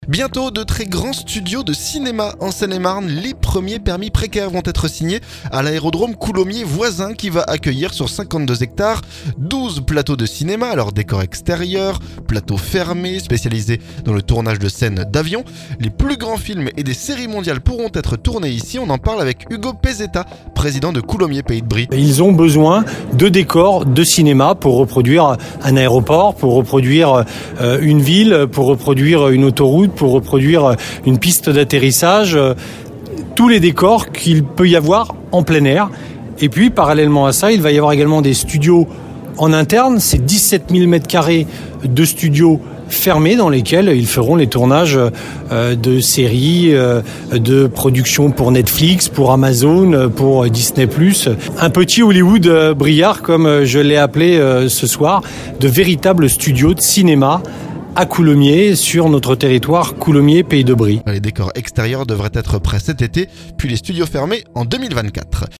Ugo Pezetta, président de Coulommiers pays de Brie nous en dit plus.